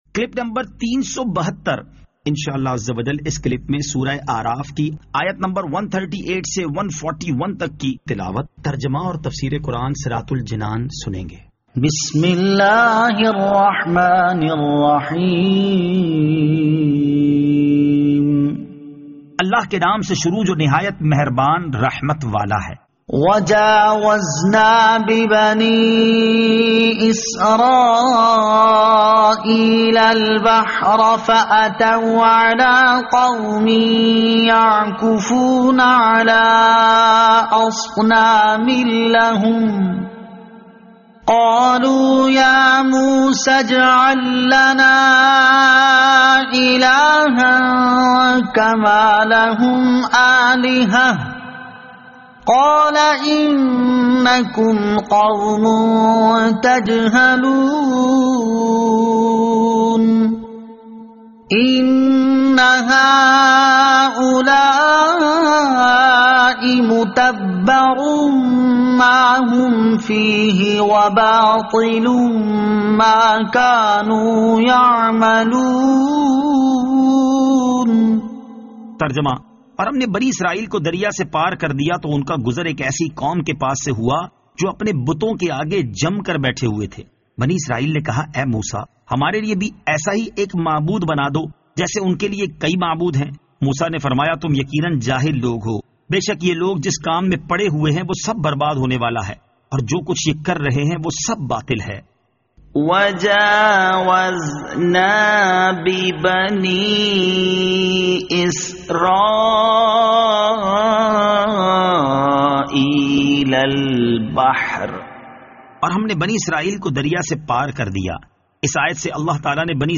Surah Al-A'raf Ayat 138 To 141 Tilawat , Tarjama , Tafseer